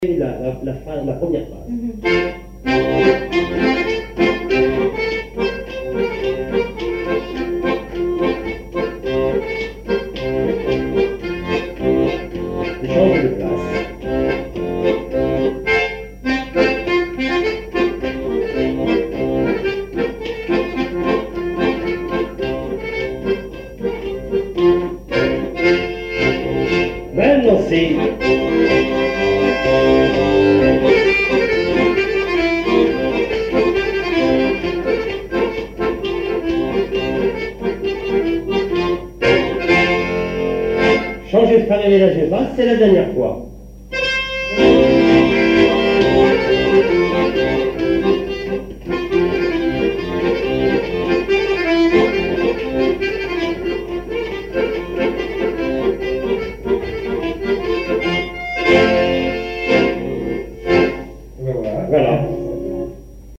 Mémoires et Patrimoines vivants - RaddO est une base de données d'archives iconographiques et sonores.
danse : quadrille : avant-quatre
répertoire à l'accordéon diatonique
Pièce musicale inédite